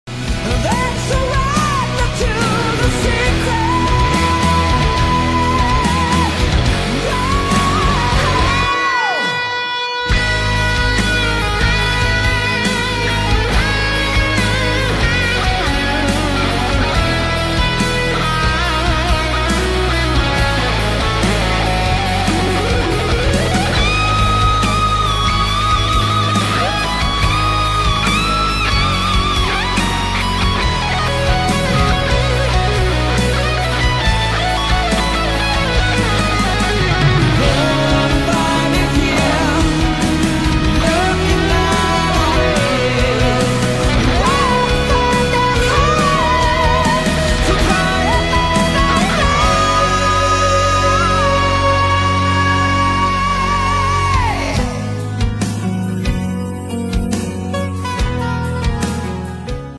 solo cover